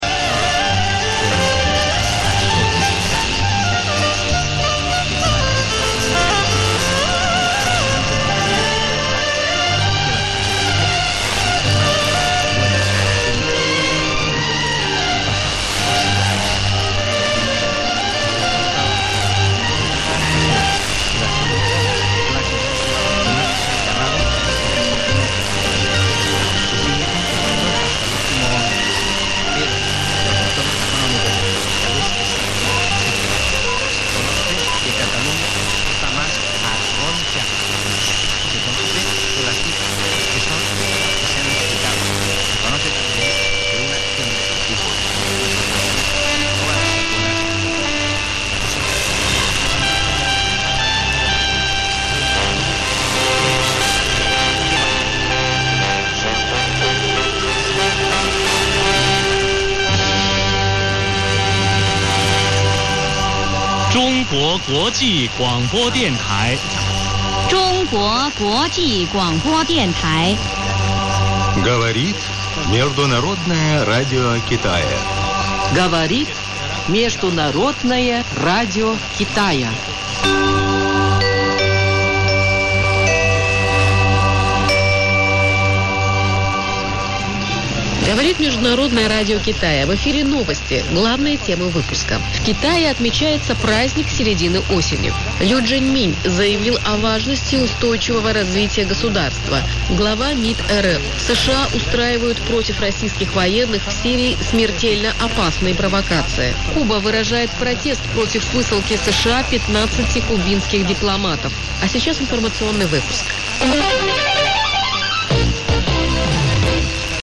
Ez is a loop antennával készült?
Írtam már a 1521 kHz - "Радио Китая" - azaz a Kínai Rádió orosz nyelvű adásának vételéről, az adást Kína észak-nyugati részéről sugározzák...a felvételt 21 órakor készítettem, behallatszik az iráni adás is + némi elektromos zavar is...